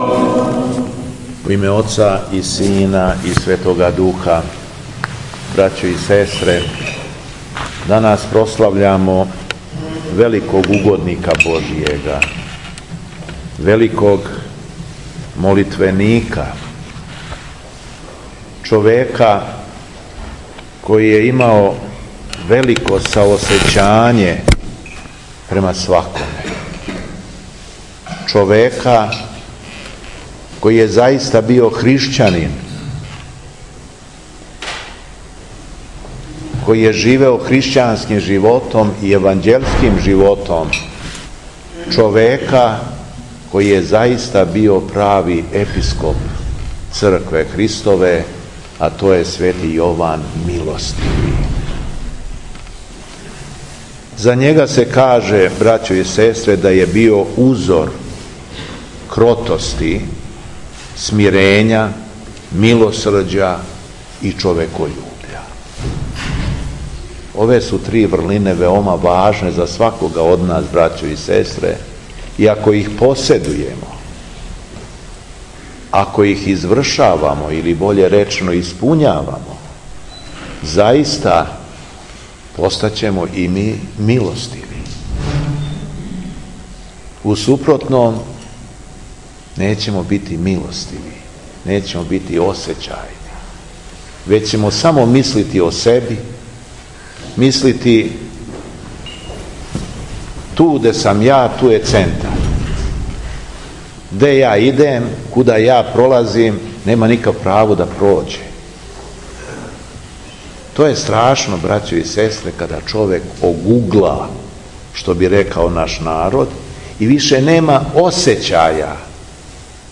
СВЕТА АРХИЈЕРЕЈСКА ЛИТУРГИЈА У АРАНЂЕЛОВЦУ
Беседа Његовог Преосвештенства Епископа шумадијског г. Јована
Дана 25. новембра 2023. године, када наша Света Црква прославља успомену на Светог Јована Милостивог, Епископ шумадијски Господин Јован је свештенослужио у Аранђеловцу у храму посвећеном Светом архангелу Гаврилу.